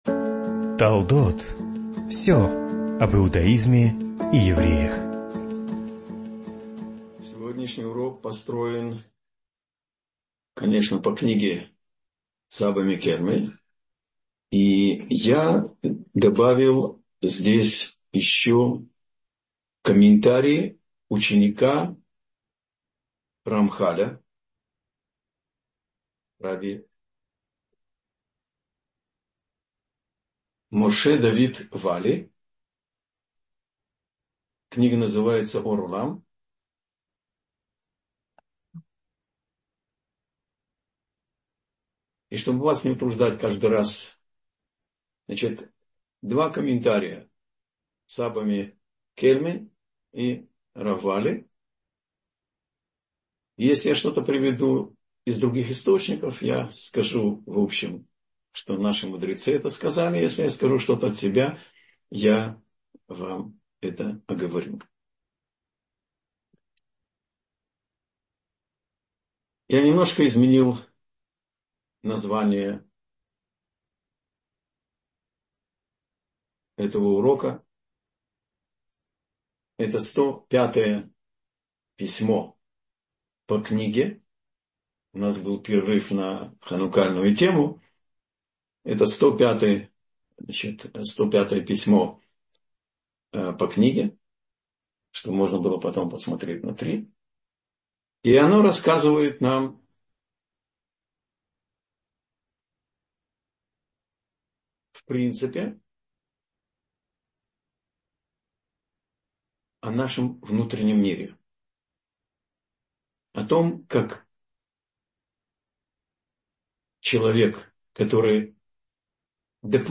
Вне границ добра властвует зло — слушать лекции раввинов онлайн | Еврейские аудиоуроки по теме «Мировоззрение» на Толдот.ру